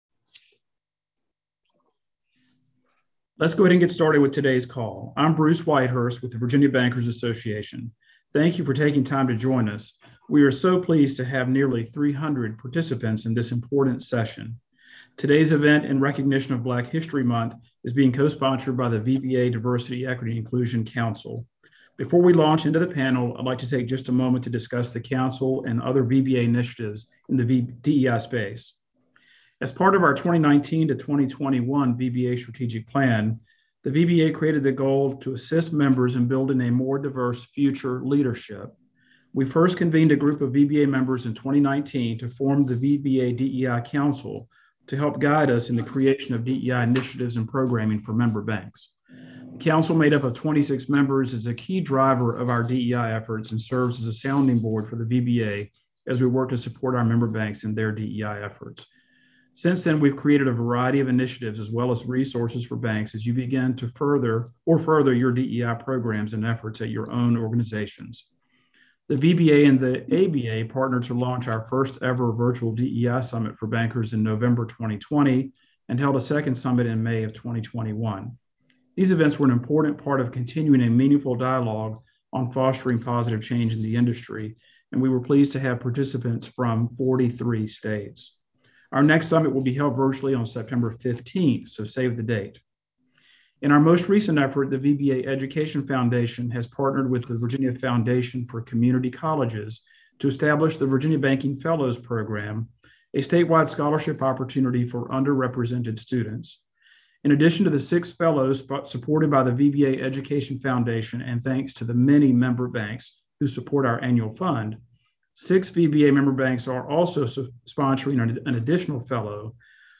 Conversation Recording